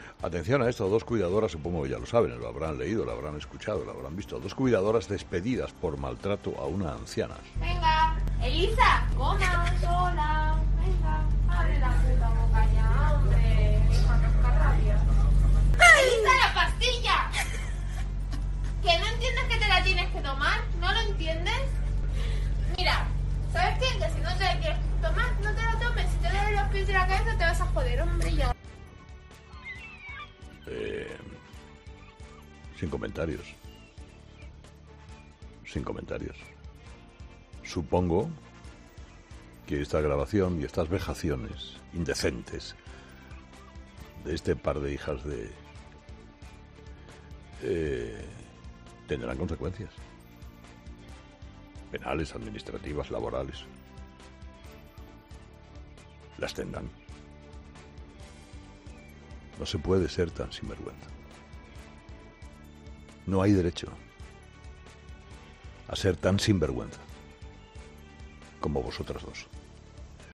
Carlos Herrera, director y presentador de 'Herrera en COPE' ha criticado en el programa de este miércoles el vídeo difundido por dos trabajadoras de una residencia en la que se muestra comportamientos vejatorios hacia una anciana.